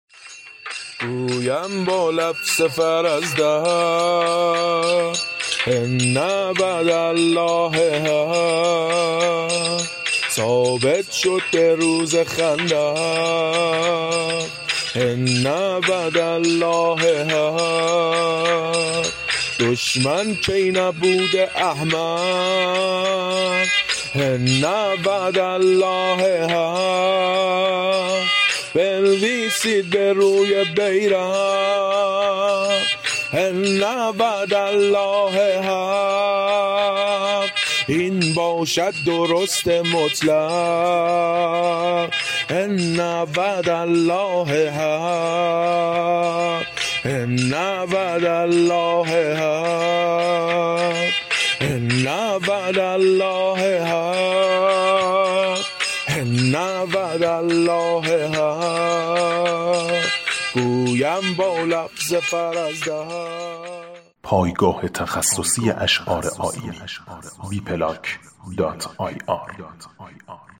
شعار گونه